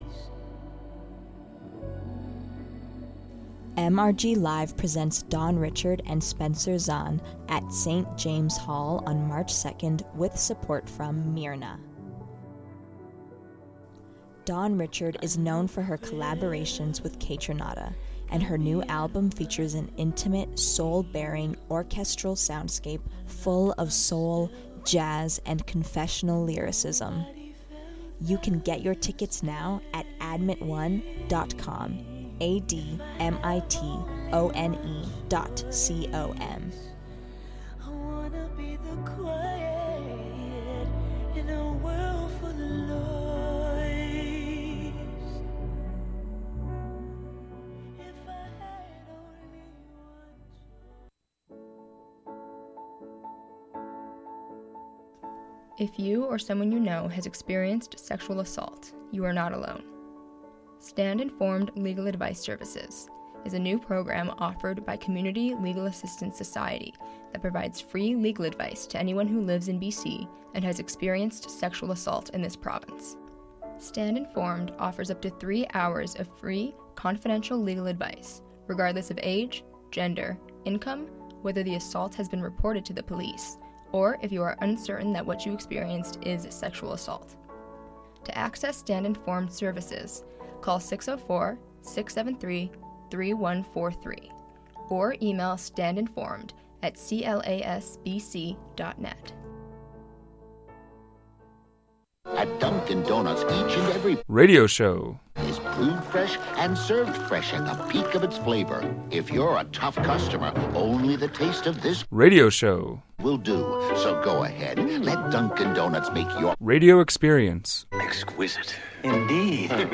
Darkroom Hour Iteration Two! Music that is fraying at the edges.